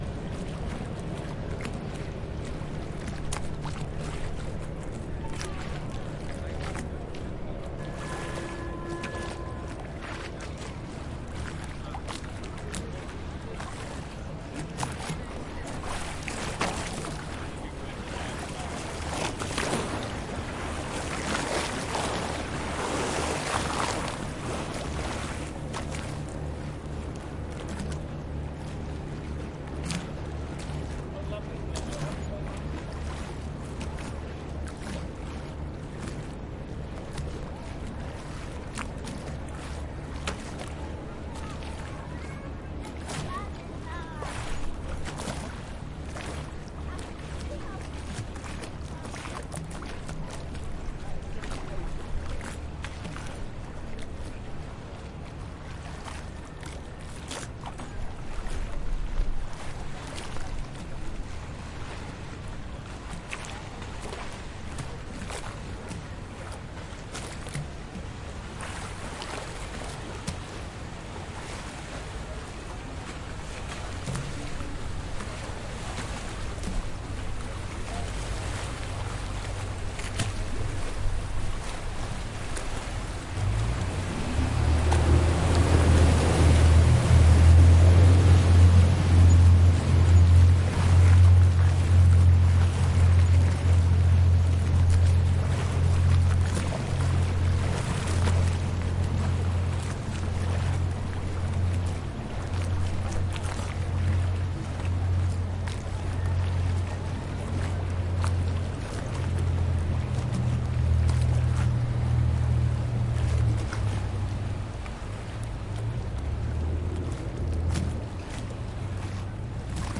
伦敦市中心的声音 " 泰晤士河（水船）
描述：泰晤士河（水与船）
标签： 环境 现场记录 城市
声道立体声